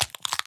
Minecraft Version Minecraft Version 25w18a Latest Release | Latest Snapshot 25w18a / assets / minecraft / sounds / mob / turtle / egg / egg_crack1.ogg Compare With Compare With Latest Release | Latest Snapshot
egg_crack1.ogg